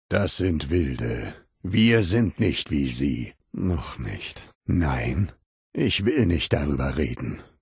Der innere Ghul: Audiodialoge